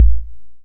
Index of /musicradar/essential-drumkit-samples/Vintage Drumbox Kit
Vintage Kick 02.wav